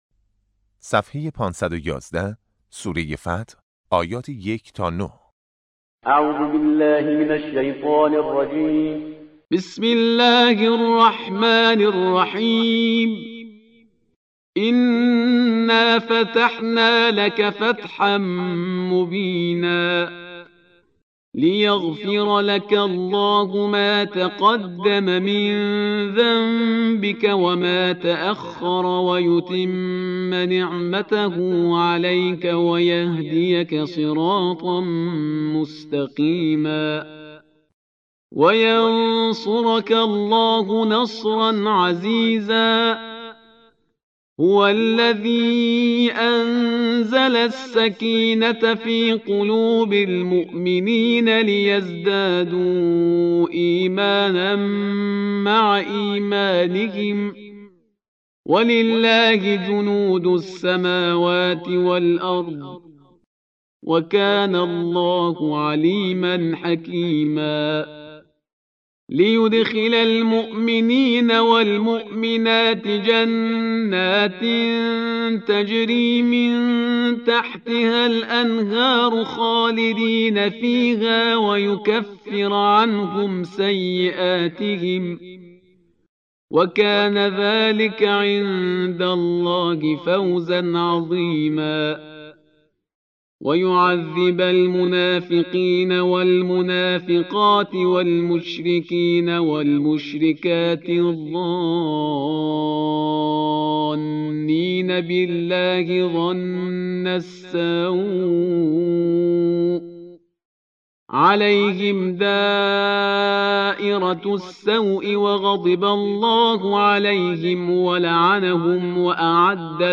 قرائت درس چهارم جلسه اول قرآن نهم